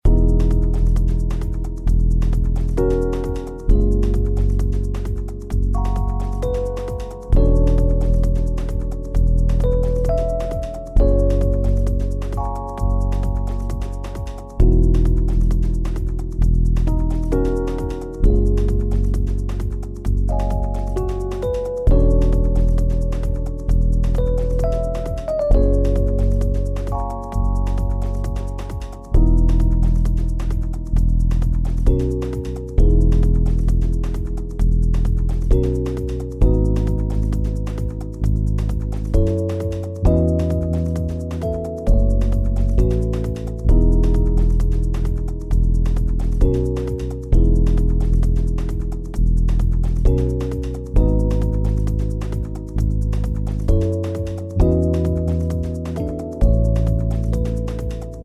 a-2-3-groovy-bgm.ogg